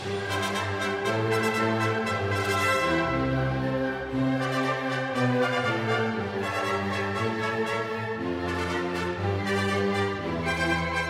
In fact, shortly after the theme for the Russians, you'll hear another familiar tune.